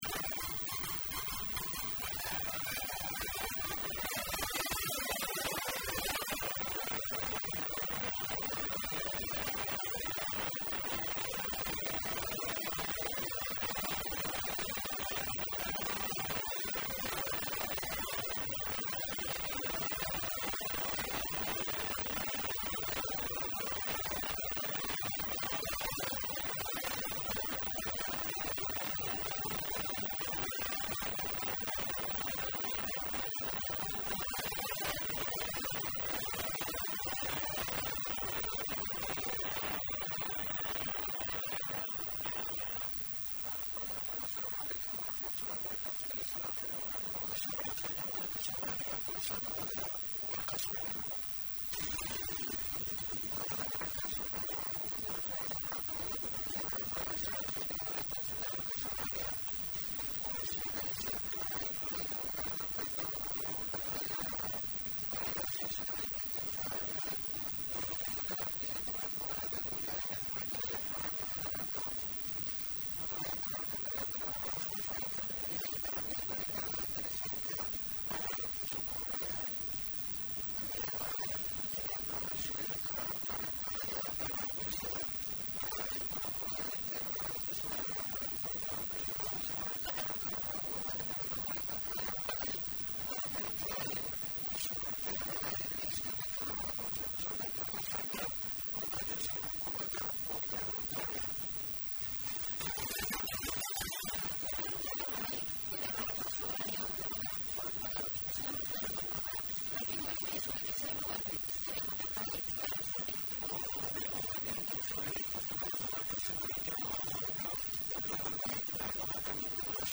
Dhageeyso warka Subax ee Radio Muqdisho